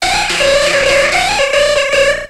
Grito de Jynx.ogg
Grito_de_Jynx.ogg